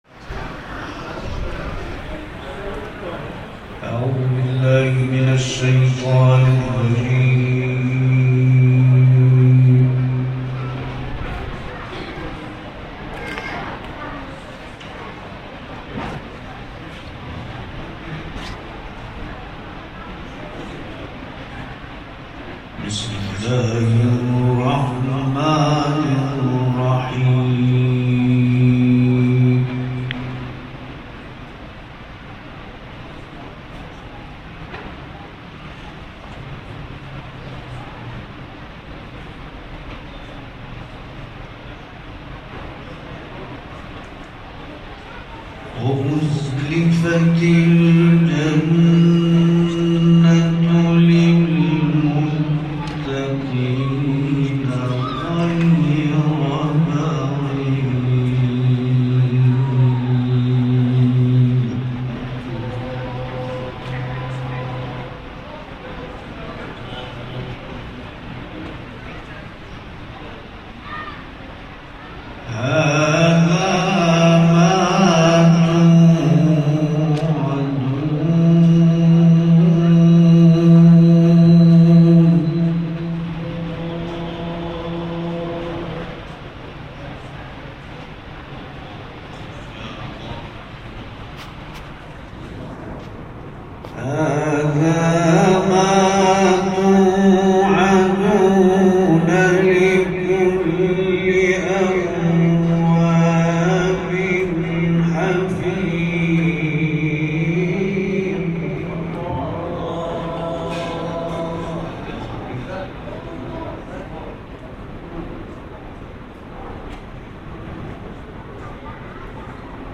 گروه فعالیت‌های قرآنی: محفل انس باقرآن کریم شب گذشته، یکم تیر در ساوه برگزار شد.